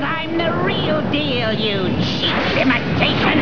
From Spider-Man: The Animated Series.